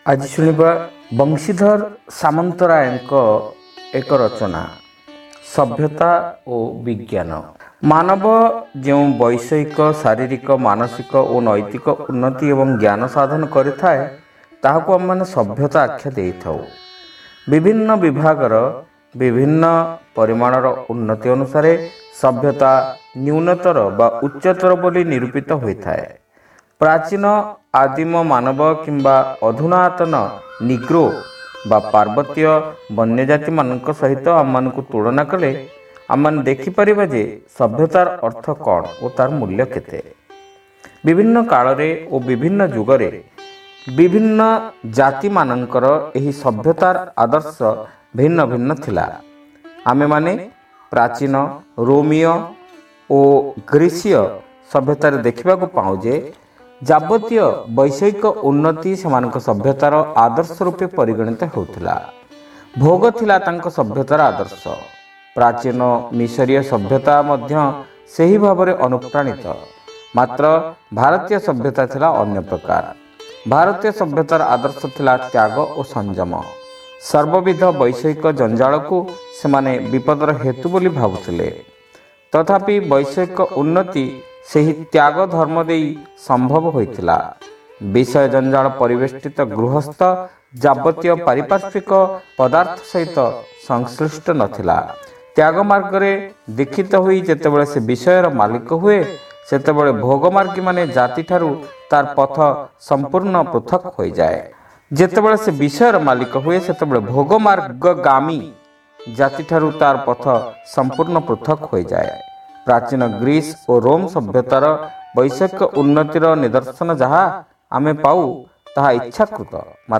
Audio Story : Sabhyata O Bigyan